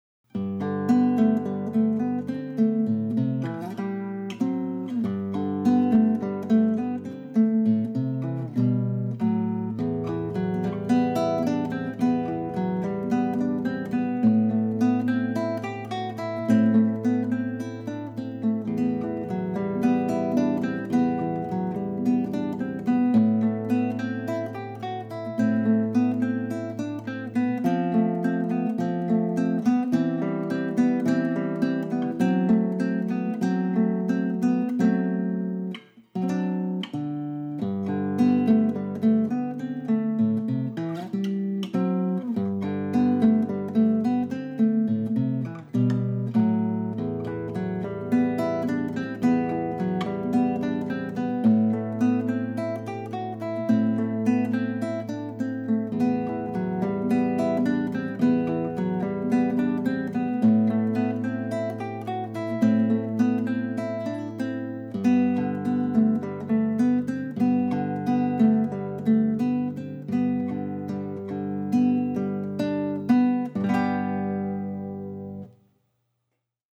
Thème A : la mélodie et contrechant sont splendides